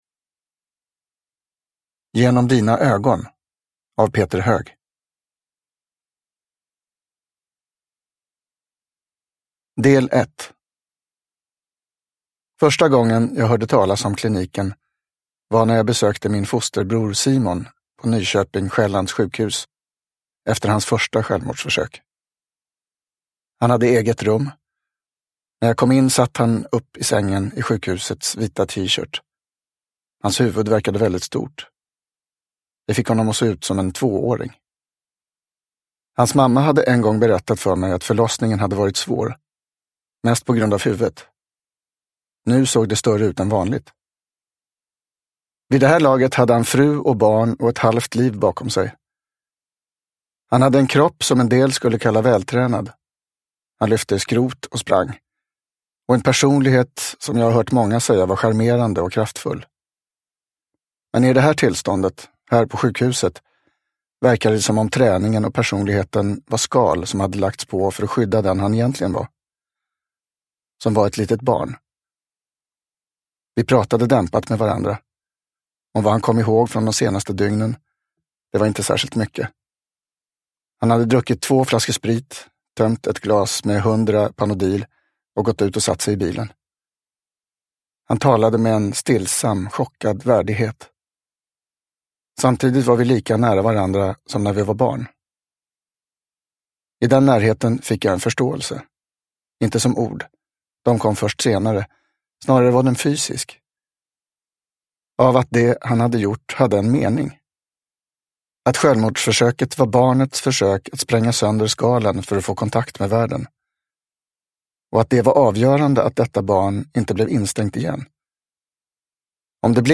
Genom dina ögon – Ljudbok – Laddas ner